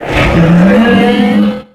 Cri de Frison dans Pokémon X et Y.